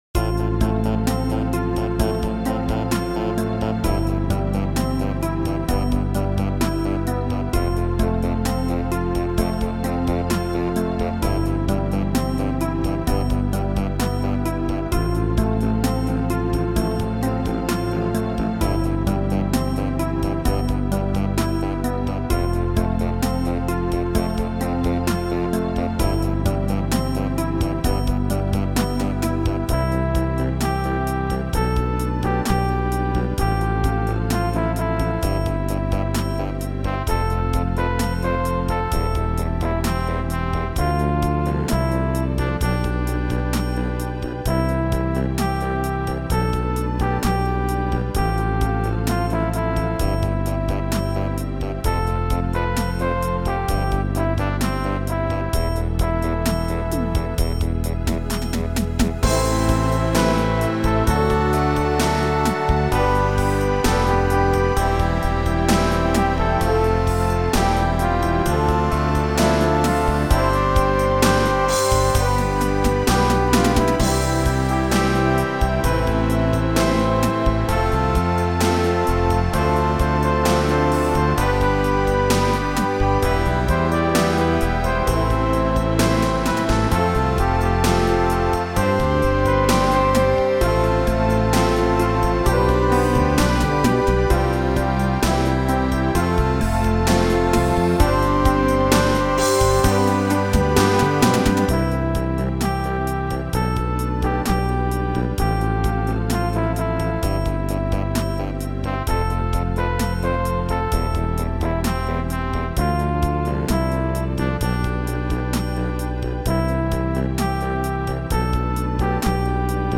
My backing is gratuitously 80s, but what the hell.